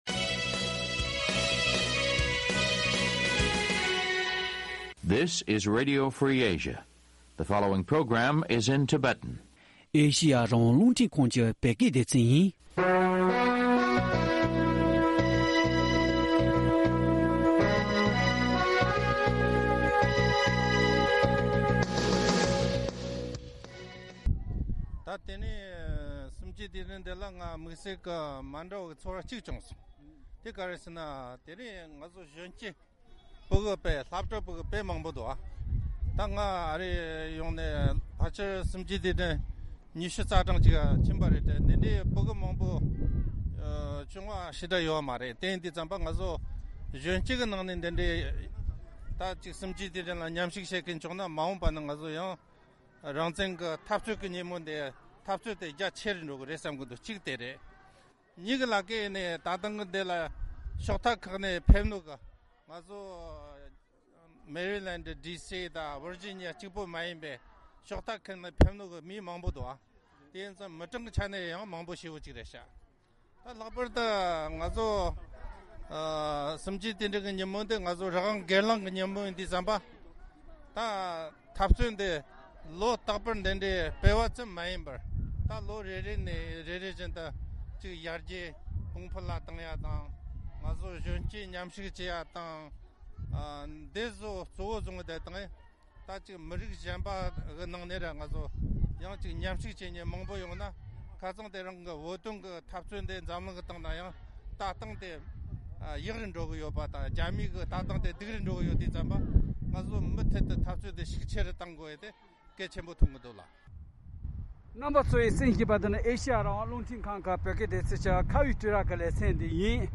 འདི་གར་གསར་འགོད་པ་དུས་དྲན་སྲུང་བརྩི་དང་ངོ་རྒོལ་གྱི་ལས་འགུལ་སྤེལ་སའི་ཡུལ་དངོས་སུ་བསྐྱོད་ནས།
གླེང་མོལ་ཞུས་པའི་ལས་རིམ།